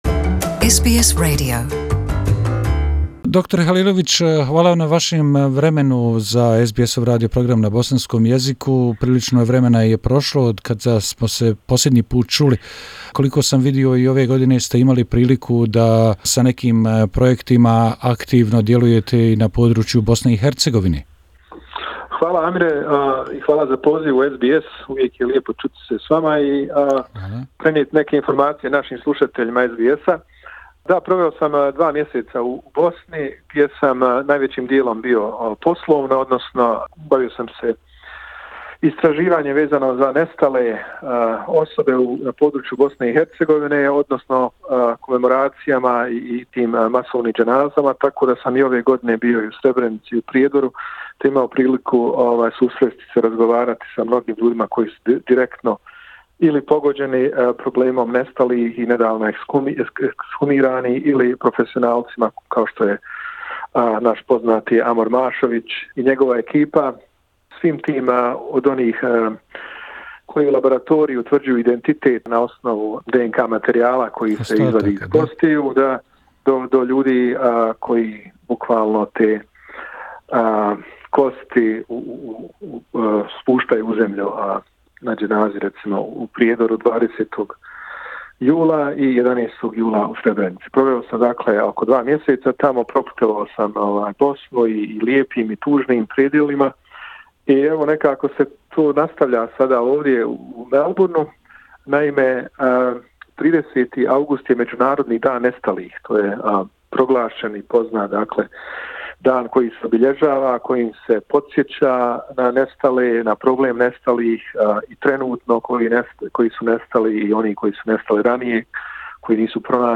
In an interview with our program